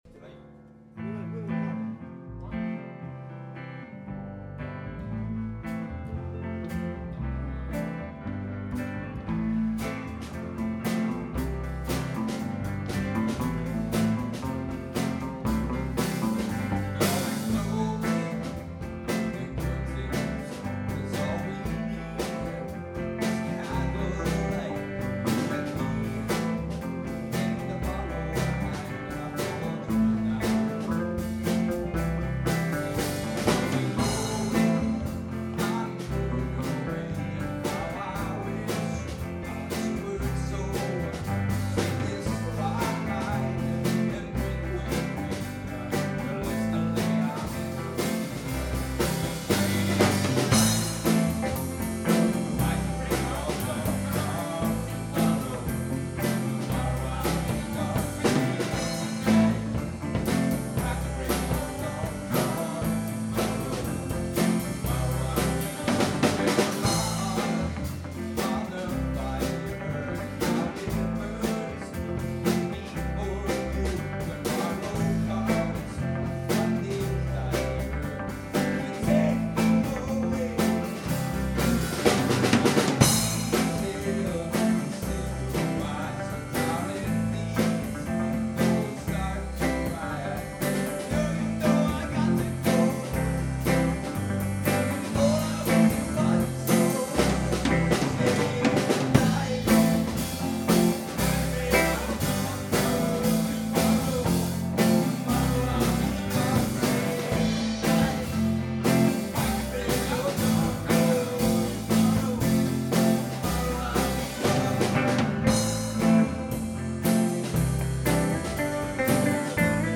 09 - (Rock mit Gesang).mp3